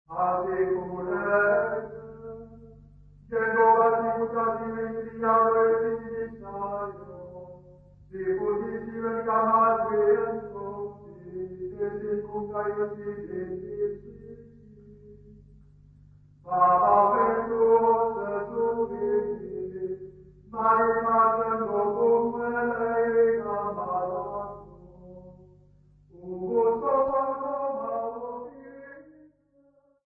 Church Choir
Hymns, Zulu South Africa
Church music South Africa
field recordings
Unaccompanied church praise hymn.